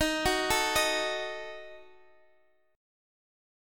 Ebdim chord